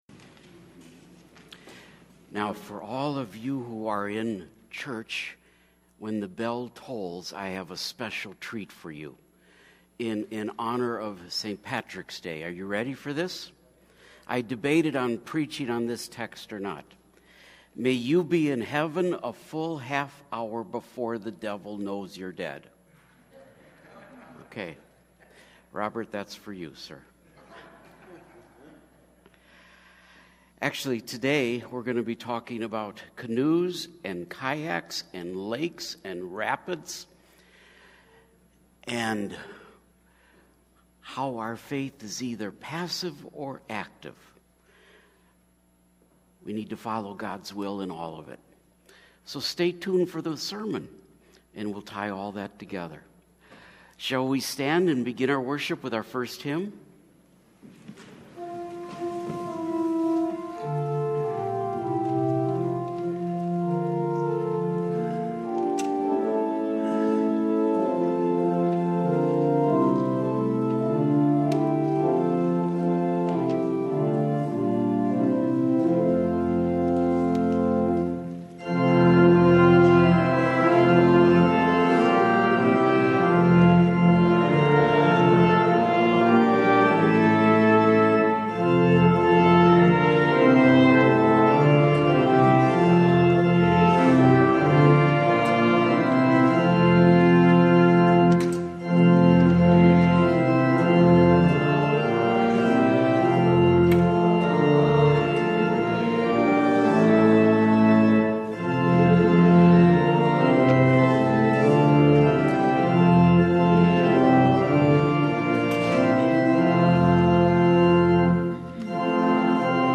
Mar 25 / Divine – Willing vs. Unwilling – Lutheran Worship audio